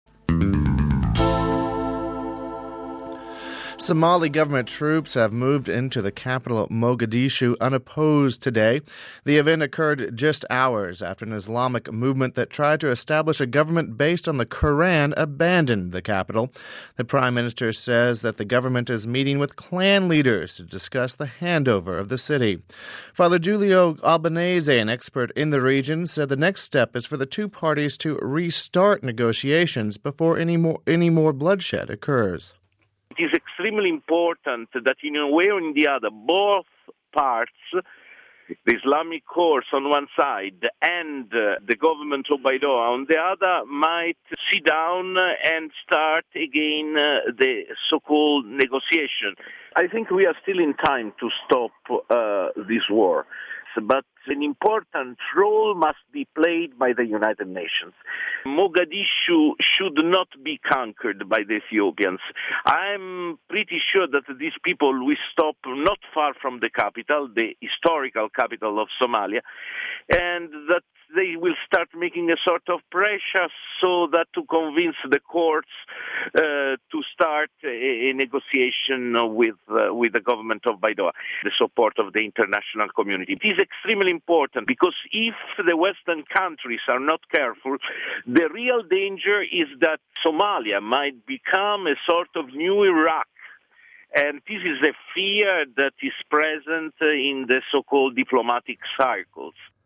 (28 Dec 06 - RV) On Thursday, Somalia's government forces, backed by Ethiopia, took the nation's capital. The forces of the Islamic Courts which had ruled the city for months fled before the troops arrived. We spoke to one expert who told what should happen next...